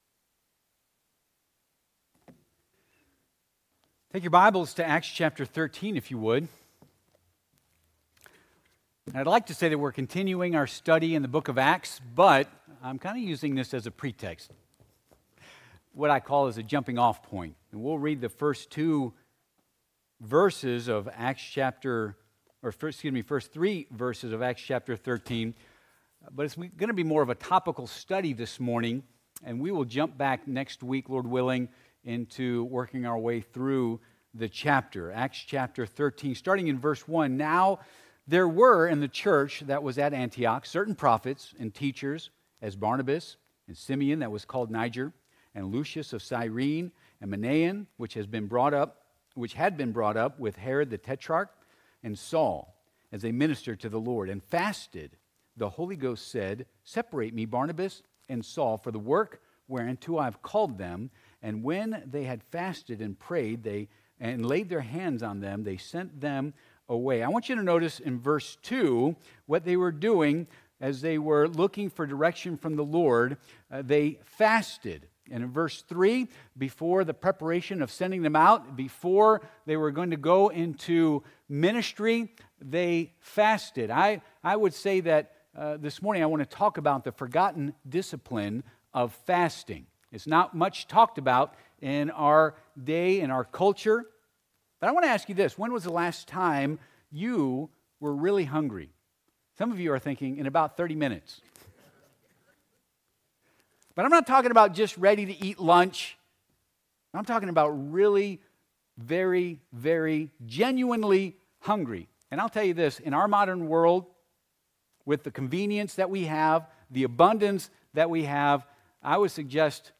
Acts 13:1-3 Service Type: Sunday AM Topics: Fasting